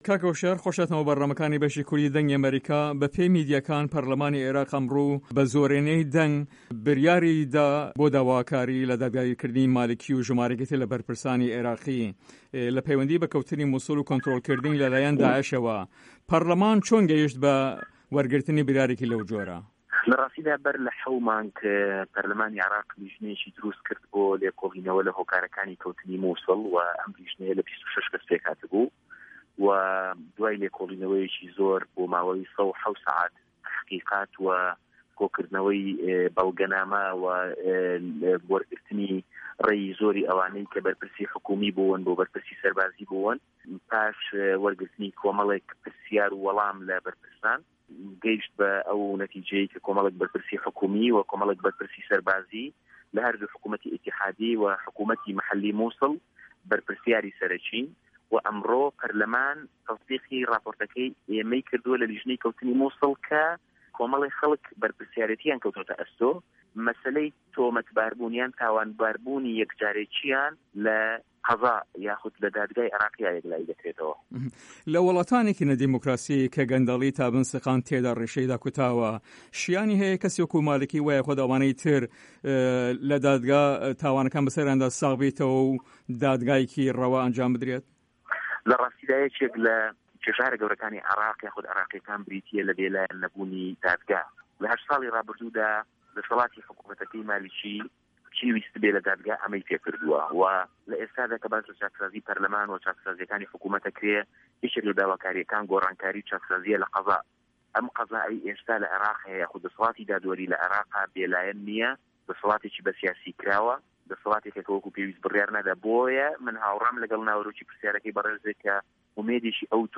هۆشیار عەبدوڵا ئەندام پەرلەمانی عێراق لە سەر لیستی گۆڕان لە هەڤپەیڤینێکدا لەگەڵ بەشی کوردی دەنگی ئەمەریکا دەڵێت" لە راستیدا بەر لە حەوت مانگ پەرلەمانی عێراق لیژنەیەکی درووستکرد بۆ لێکۆڵینەوە لە هۆکارەکانی کەوتنی موسڵ وە، وە ئەو لیژنەیە لە 26 کەس پێکهاتبوو وە دووای لێکۆڵینەوەیەکی زۆر بۆ ماوەی 107 کاتژمێر لێکۆڵینەوە و کۆکردنەوەی بەڵگەنامە وە وەررگرتنی رای زۆری ئەوانەی کە بەرپرسی حکومی و سەربازی بوون، وە پاش وەرگرتنی کۆمەڵێک پرسیار و وەڵام گەیشت بەو دەر ئەنجامە کە کۆمەڵێک بەرپرسی حکومی و کۆمەڵێک بەرپرسی سەربازی لە هەردوو حکومەتی فیدرالی و حکومەتی خۆچەیی موسڵ بەرپرسیاری سەرەکین.